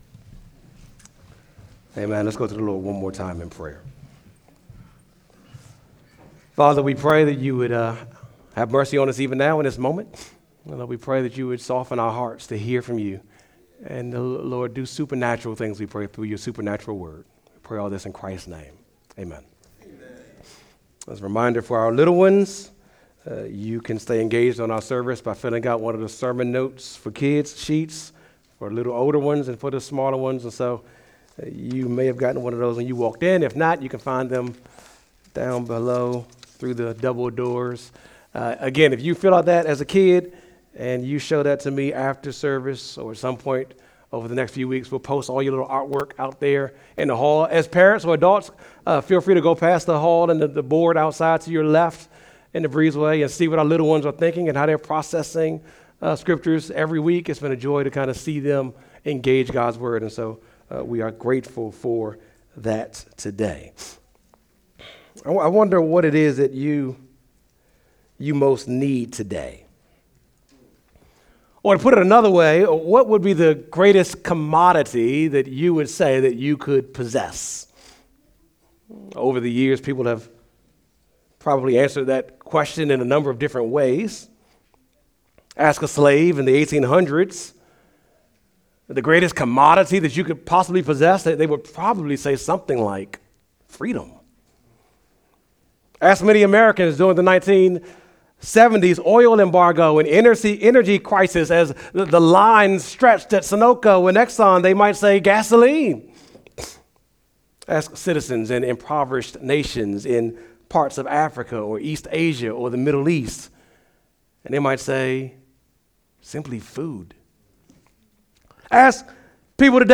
sunday-sermon-8-3-25.mp3